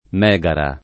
Megara [
m$gara] top. e pers. f. mit. — Megara (gr. mod. Μέγαρα / Mégara [m$Gara]), la città fra Atene e Corinto, fondatrice in antico d’importanti colonie — Megara Iblea [m$gara ibl$a] (lat. Megara Hyblaea [id.]), la sua colonia siciliana, di cui restano le rovine tra Augusta e Siracusa